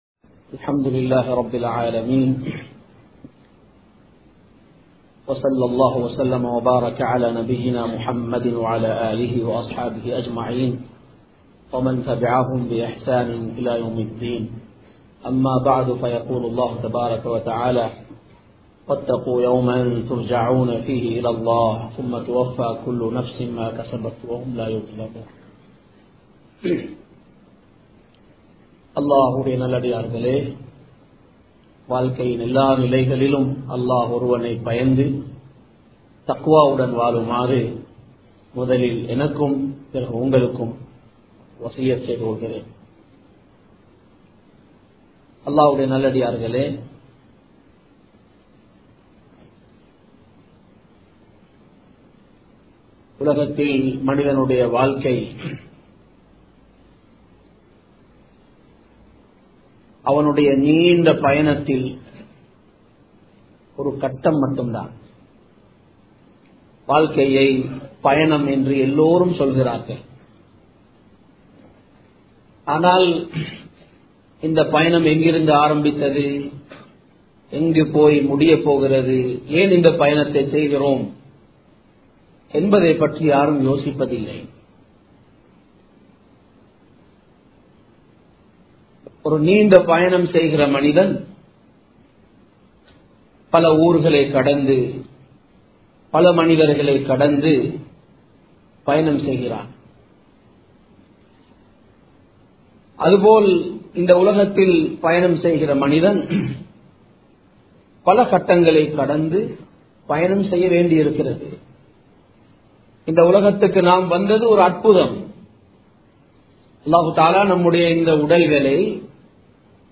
மறுமை நாள் | Audio Bayans | All Ceylon Muslim Youth Community | Addalaichenai
Kirulapana Thaqwa Jumua Masjith